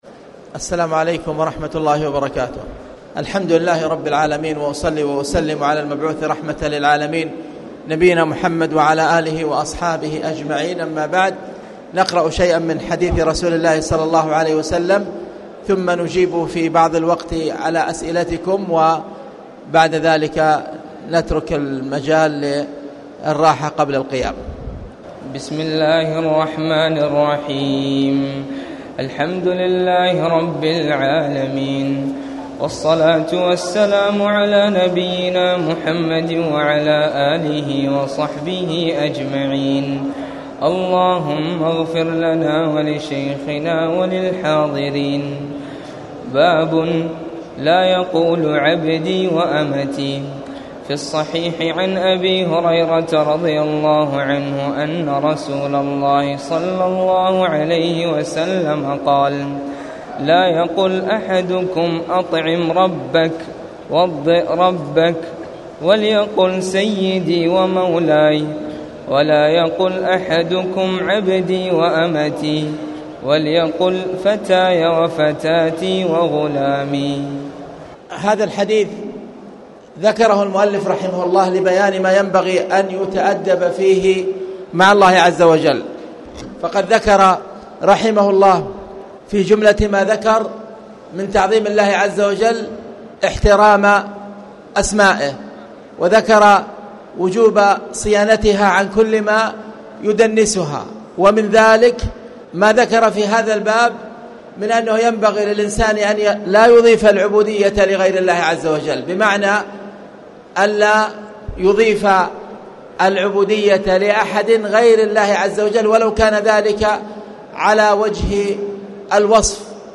تاريخ النشر ٢٠ رمضان ١٤٣٨ هـ المكان: المسجد الحرام الشيخ